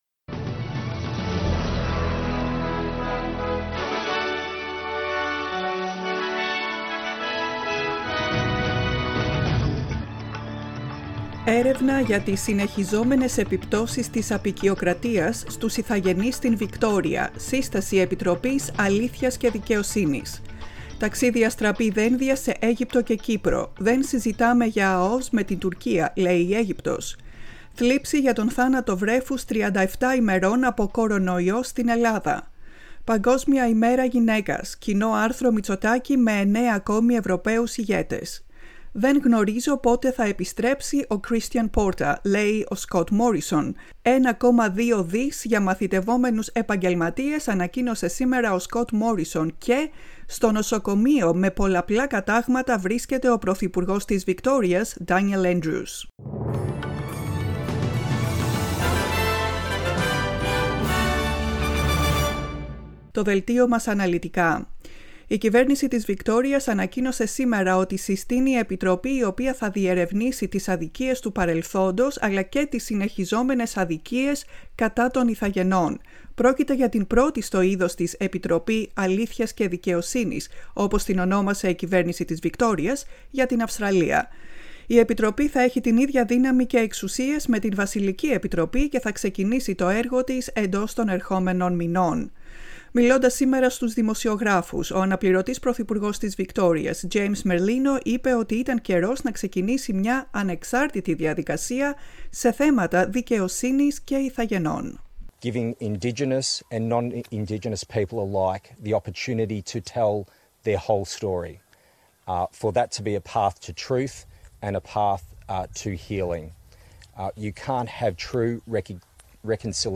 Δελτίο ειδήσεων, 9.3.21